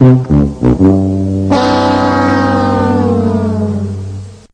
Price is Right Losing Horn